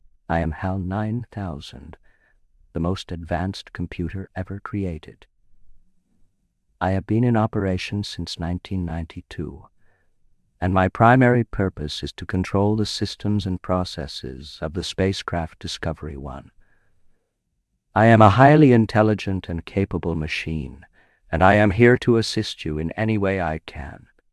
HAL9000_XTTSV2_FT.wav